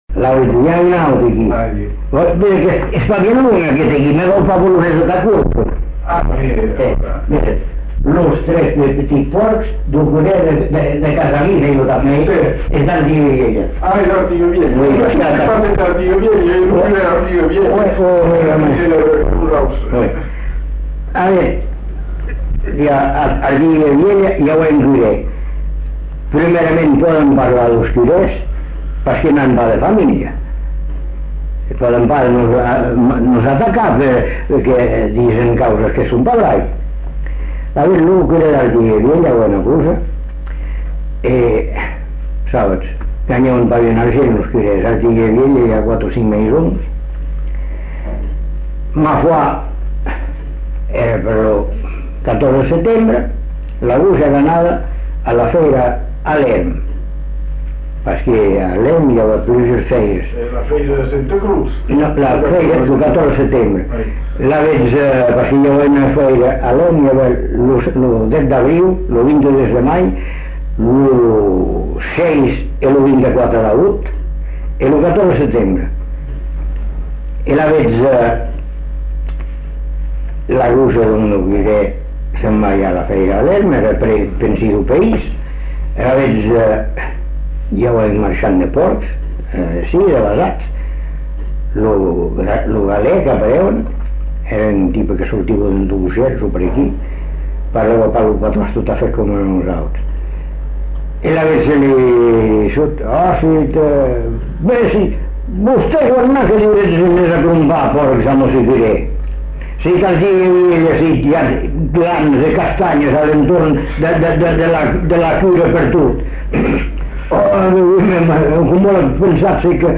Aire culturelle : Bazadais
Lieu : Bazas
Genre : conte-légende-récit
Effectif : 1
Type de voix : voix d'homme
Production du son : parlé